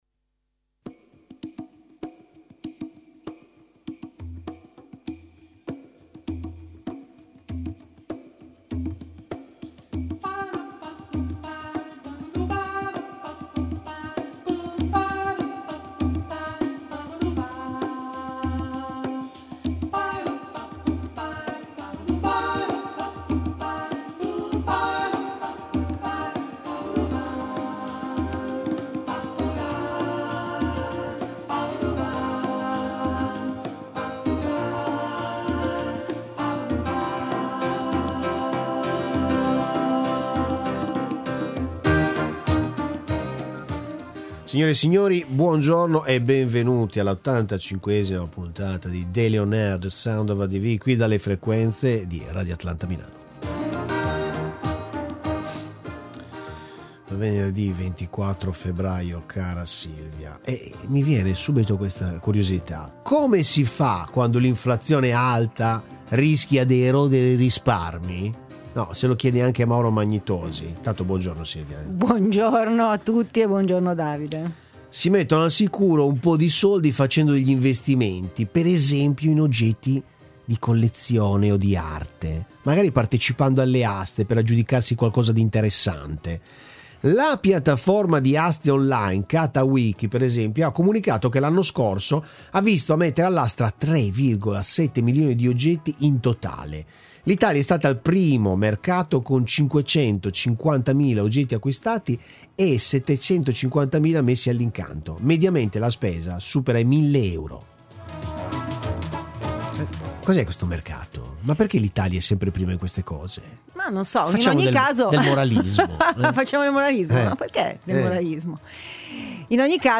Boom di aste online nel 2022 i dati Catawiki; Intervista